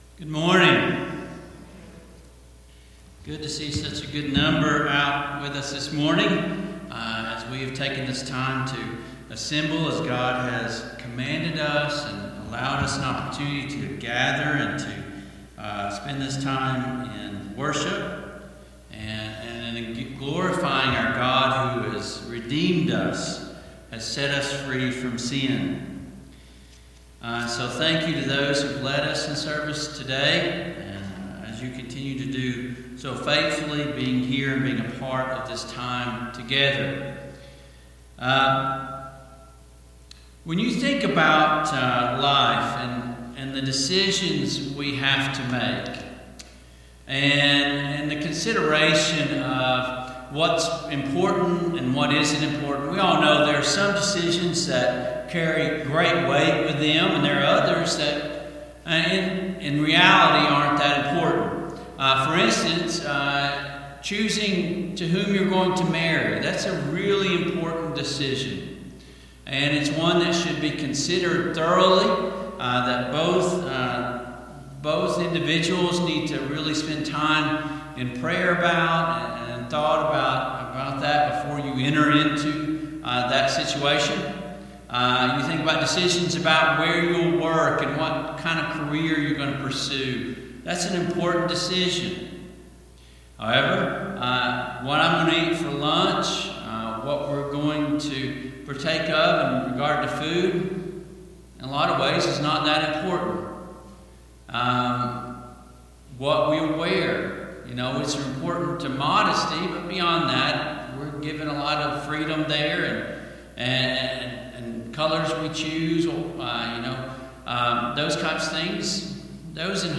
Service Type: AM Worship Topics: Commandments , Obedience to God , The Word of God